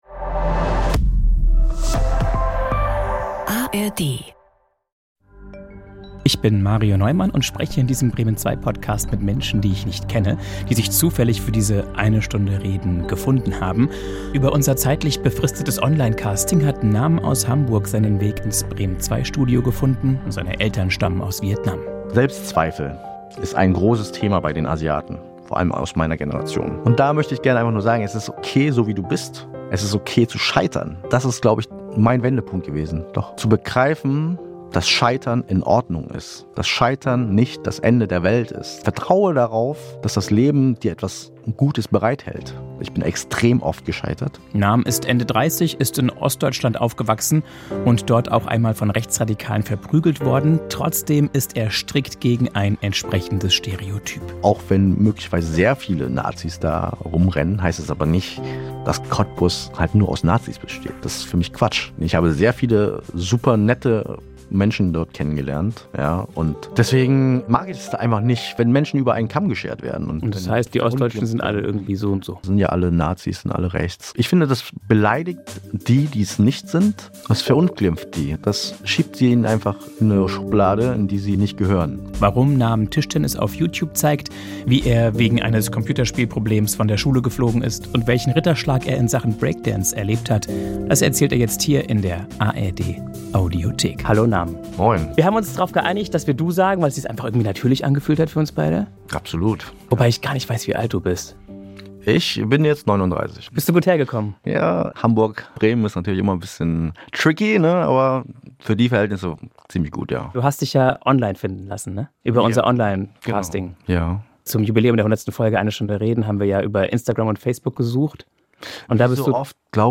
Selbstbewusst durchs Scheitern ~ Eine Stunde reden – Gespräche mit Unbekannten Podcast